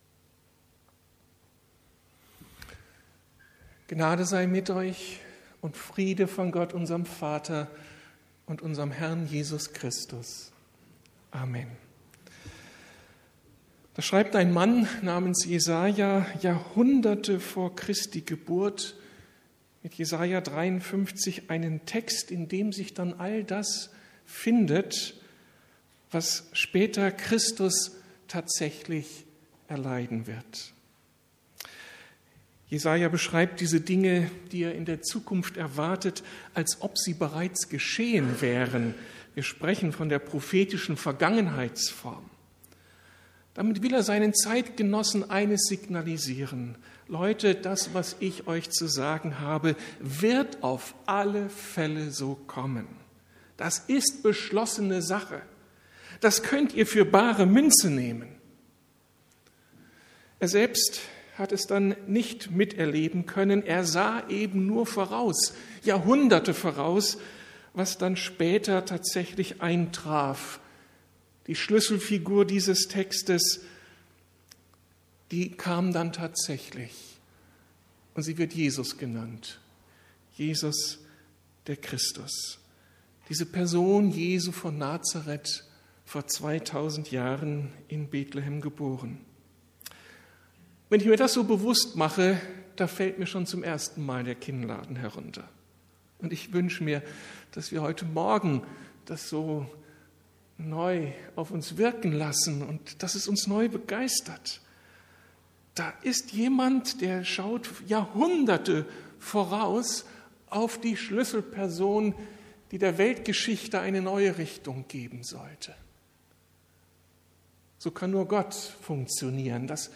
Da kann man nur noch staunen! ~ Predigten der LUKAS GEMEINDE Podcast